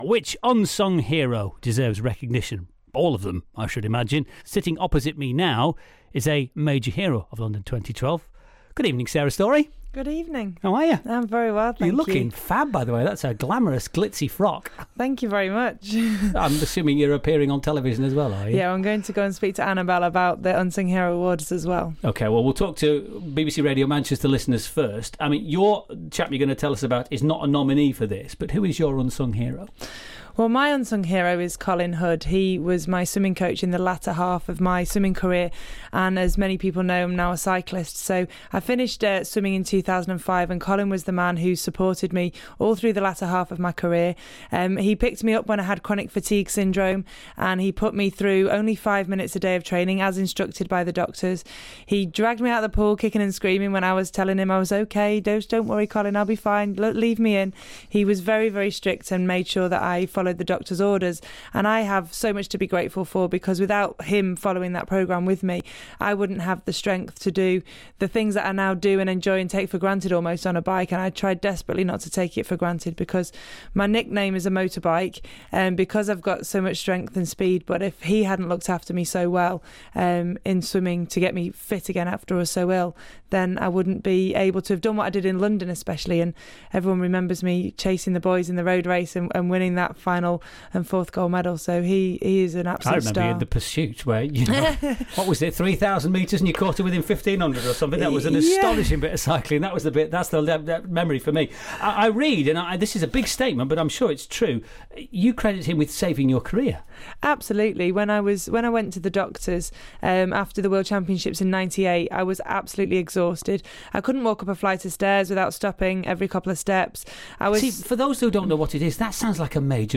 Andy Crane has been Speaking with multi Paralympic Gold medallist Sarah Storey about her own Unsung hero and how you can get involved